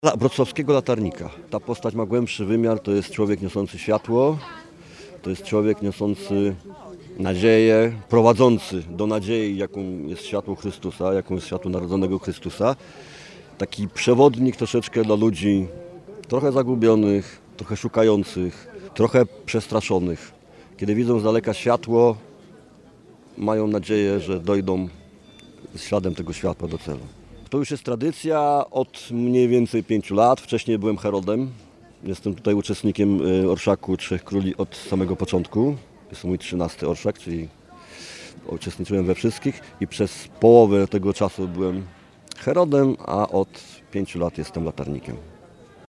Po raz 13. przez Wrocław przeszedł Orszak Trzech Króli.